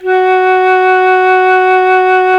Index of /90_sSampleCDs/Roland LCDP04 Orchestral Winds/CMB_Wind Sects 1/CMB_Wind Sect 4